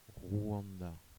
wymowa:
Fr-Rwanda.ogg.mp3